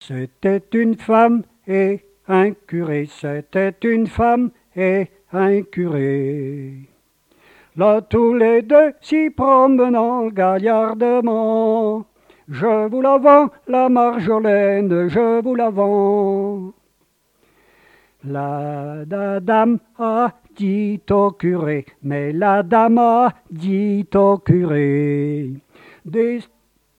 danse : ronde : grand'danse
répertoire de chansons et airs à l'accordéon
Pièce musicale inédite